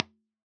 Здесь отклик, снятый с пресета "default" кабсекции Granophyre подачей в неё импульса длиной в 1 сэмпл и обрезанный после затухания (20216 сэмплов = 458 мс).